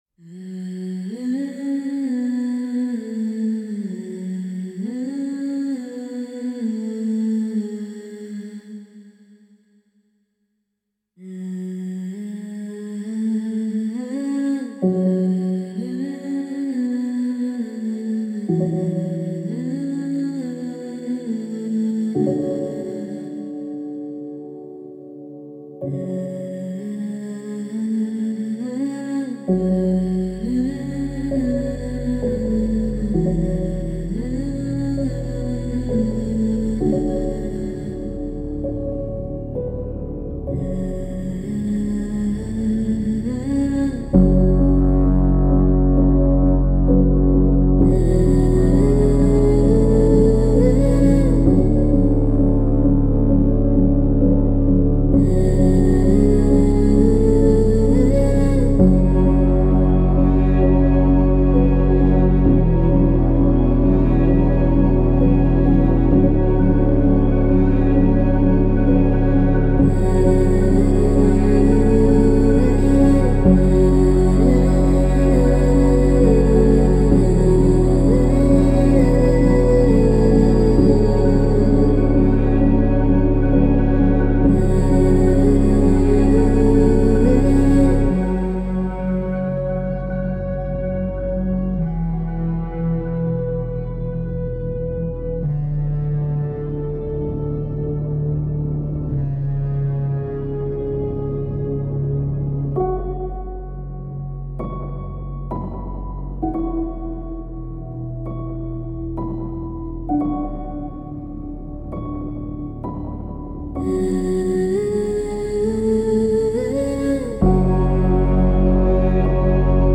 Melancholic female vocal textures and subtle piano.